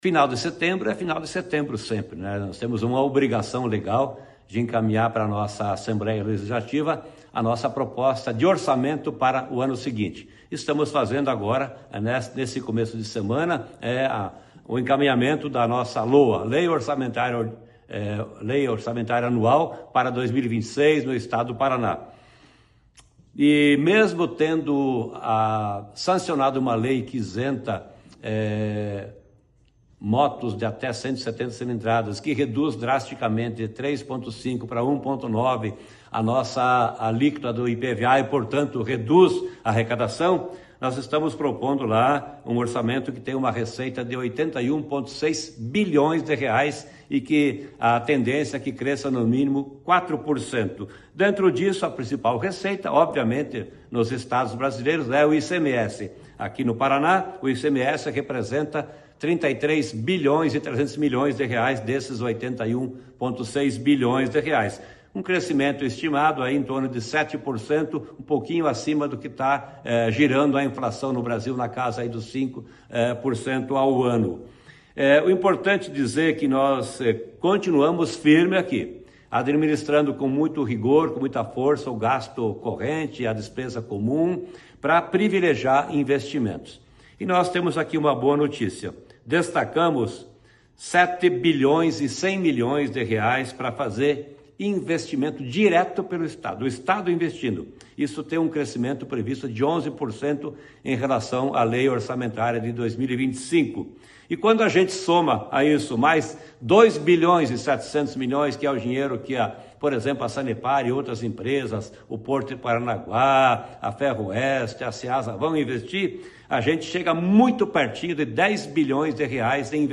Sonora do secretário da Fazenda, Norberto Ortigara, sobre o envio da LOA à Assembleia Legislativa do Paraná